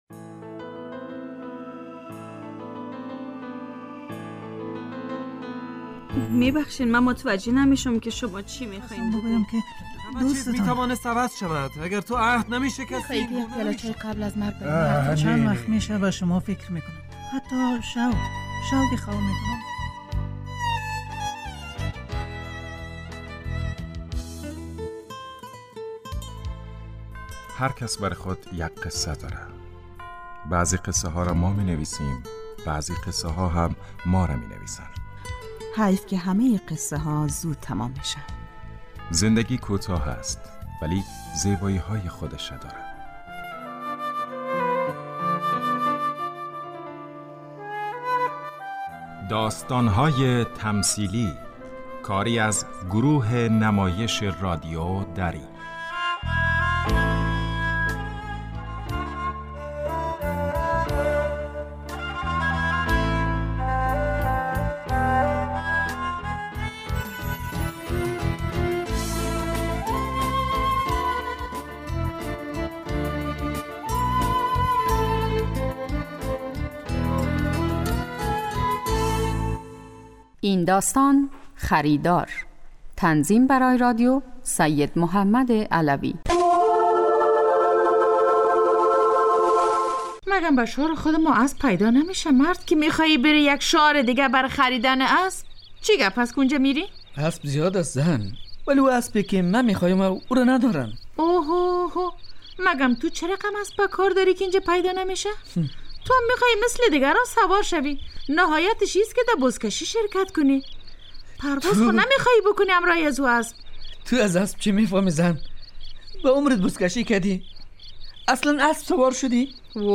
داستان تمثیلی